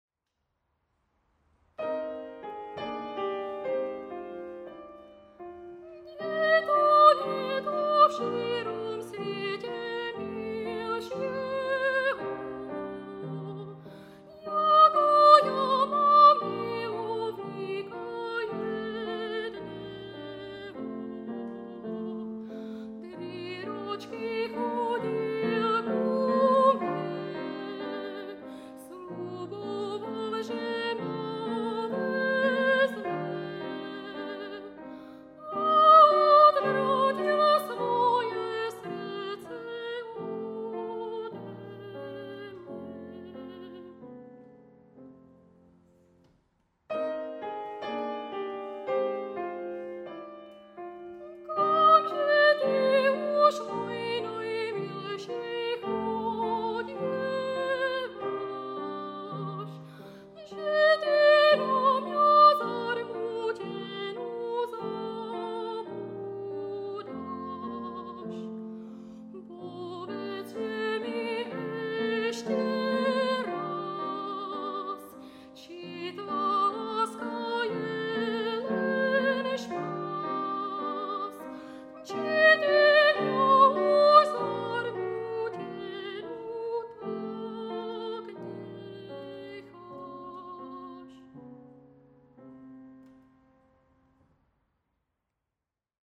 a) Bakalářský pěvecký koncert